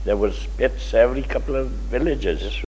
the-phonology-of-rhondda-valleys-english.pdf